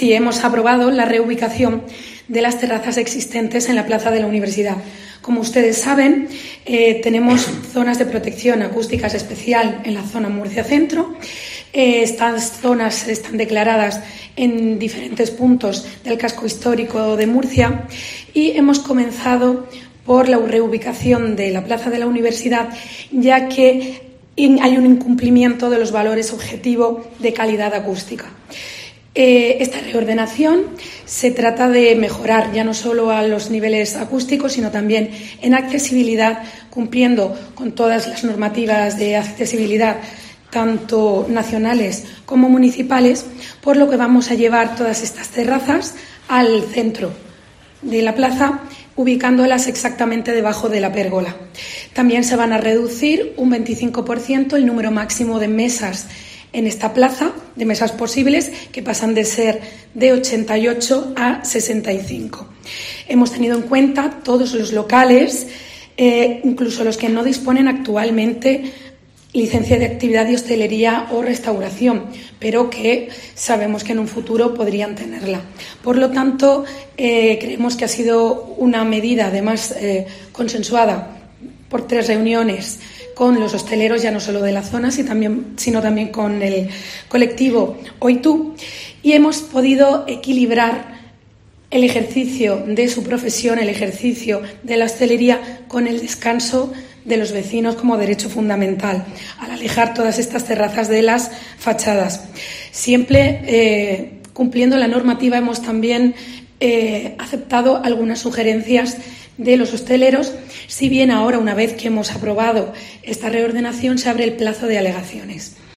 Sofía López-Briones, concejal de Talento Joven y Espacios Públicos